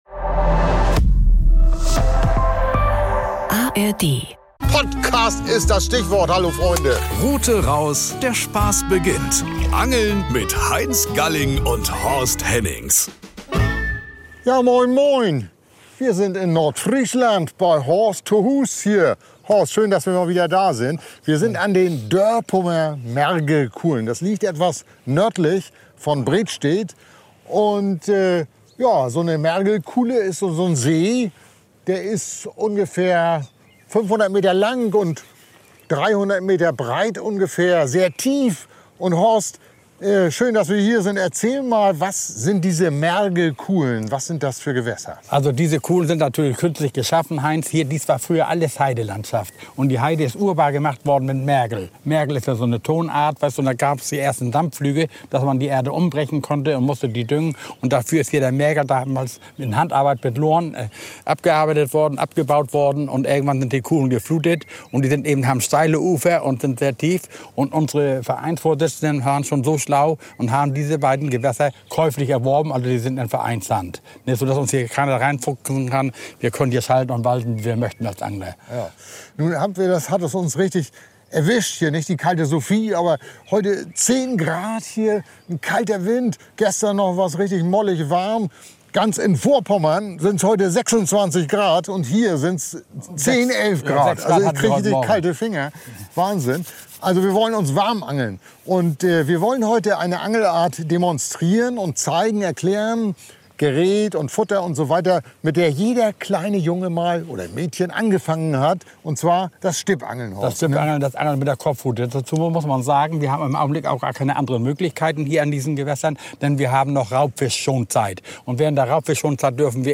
An den Dörpumer Mergelkuhlen, einem Gewässer in Schleswig-Holstein nördlich von Bredstadt, sprechen sie über Ausrüstung, Anfüttern, Köder und Angelmethoden des Stippangelns. Obwohl die Bedingungen alles andere als optimal sind, gehen einige große Brassen an den Haken.